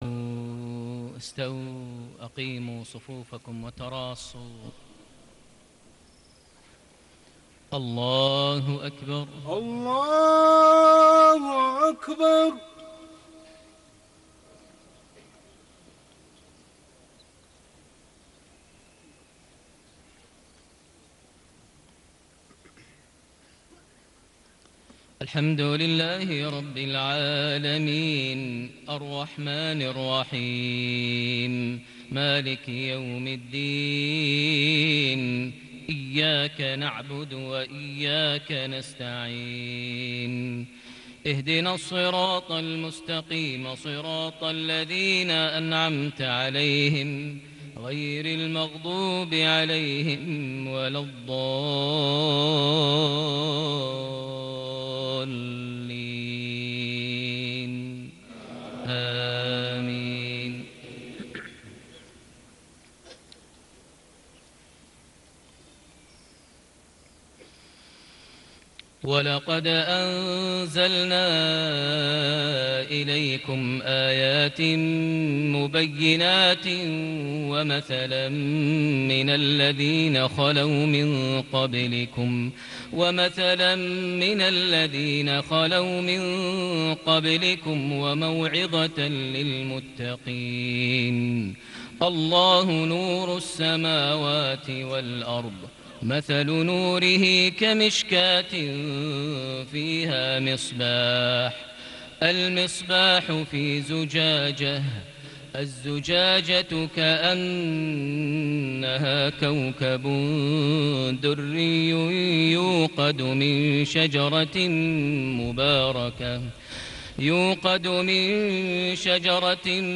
صلاة المغرب ٢٩ شعبان ١٤٣٨هـ سورة النور ٣٤-٤٠ > 1438 هـ > الفروض - تلاوات ماهر المعيقلي